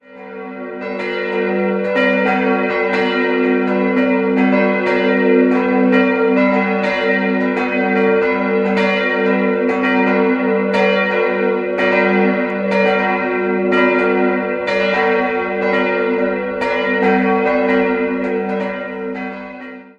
3-stimmiges Geläut: fis'-ais'-h' Die große Glocke wurde 1548 von Hans, die kleine 1590 von Christoph Glockengießer in Nürnberg gegossen.